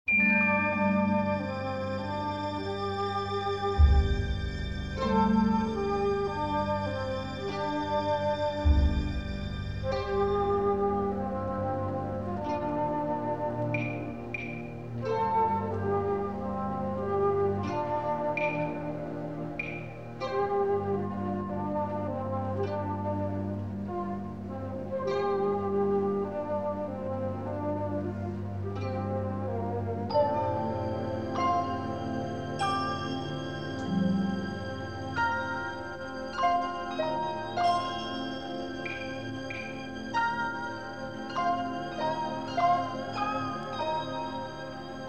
haunting Asian textures and harmonies
The entire CD is in stereo